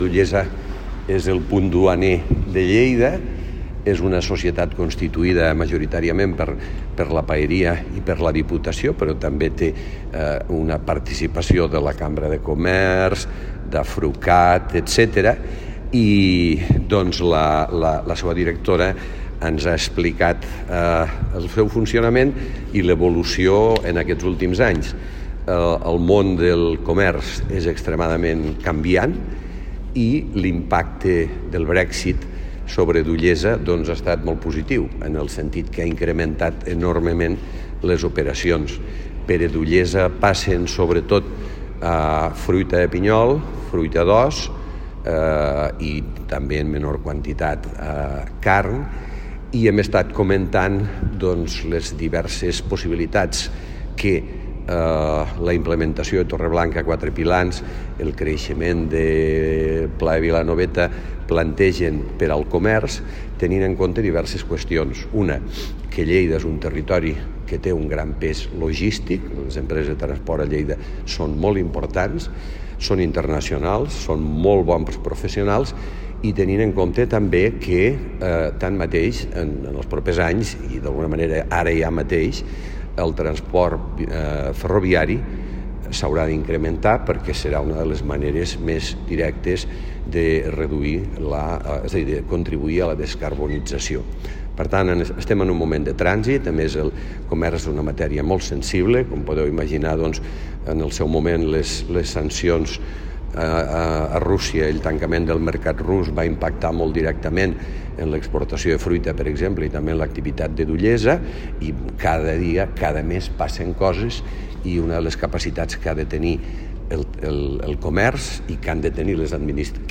tall-de-veu-del-paer-en-cap-miquel-pueyo